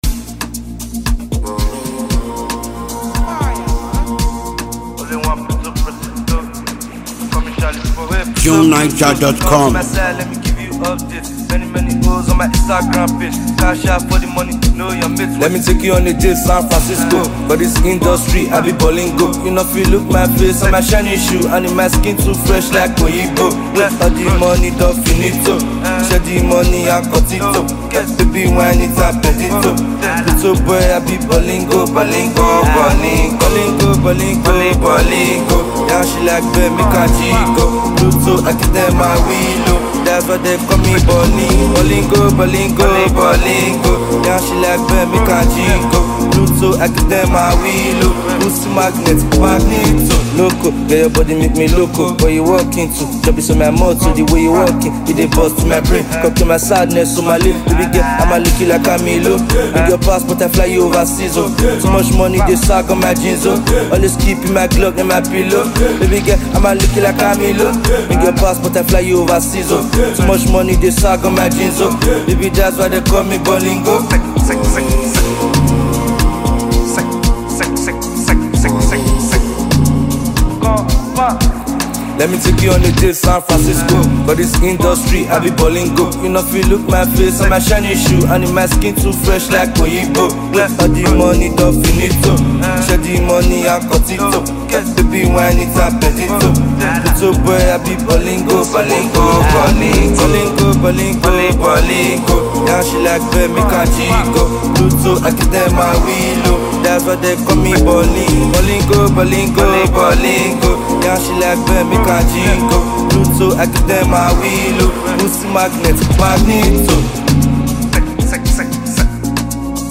brand-new jam and catchy ballad
catchy vibes and street beats